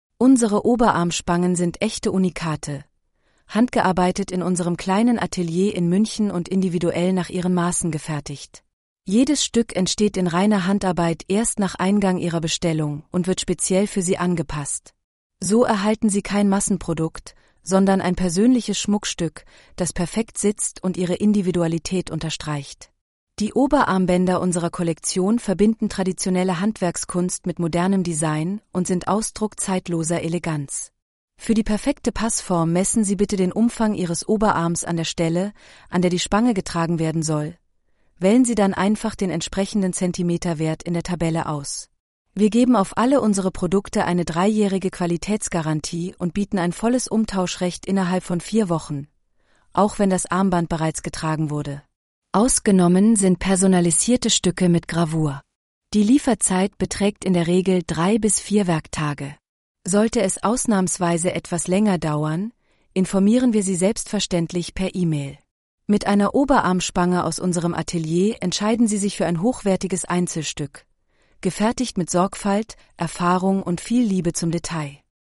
Oberarm-ttsreader.mp3